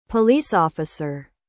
11. police officer /pəˈliːs ɒfɪsə(r)/: nhân viên cảnh sát